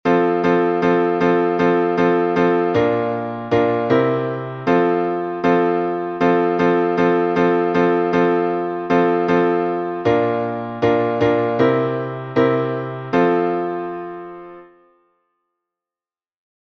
Обиходный напев №1